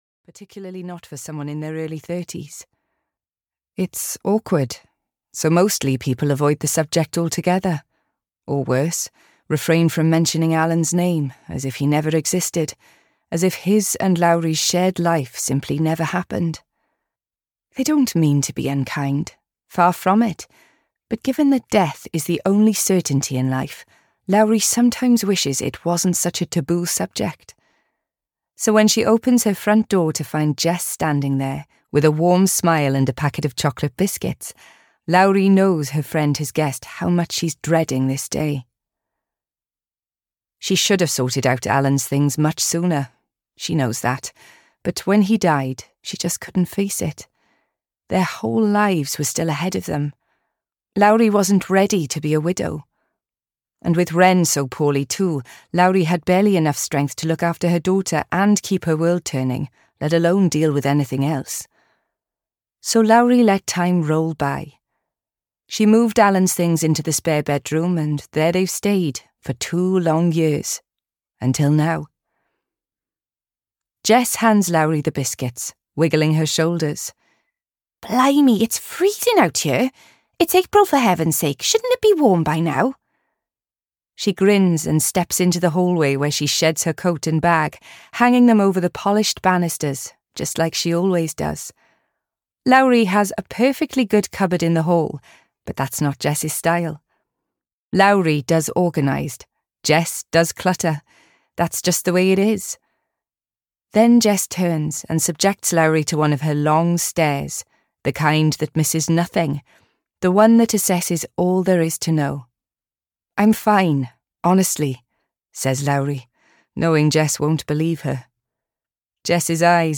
Secrets of Clearwater Castle (EN) audiokniha
Ukázka z knihy